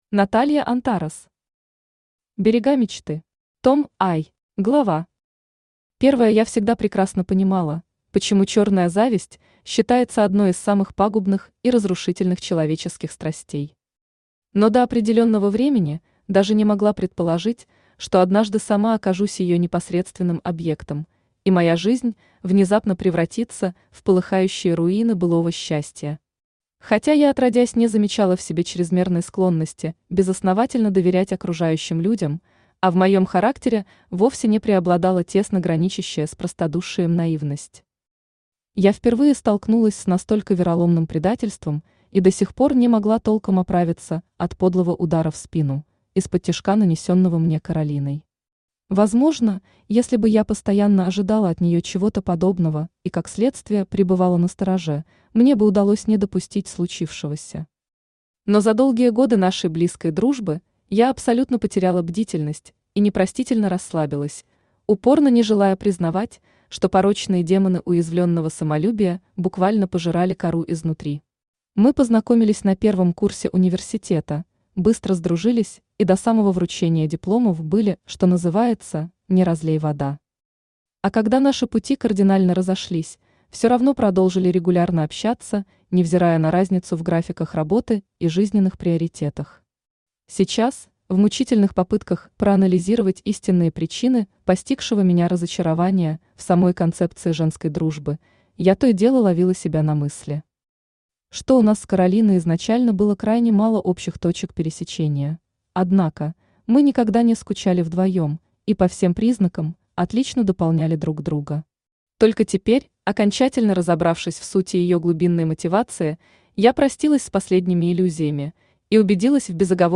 Аудиокнига Берега мечты. Том I | Библиотека аудиокниг
Том I Автор Наталья Антарес Читает аудиокнигу Авточтец ЛитРес.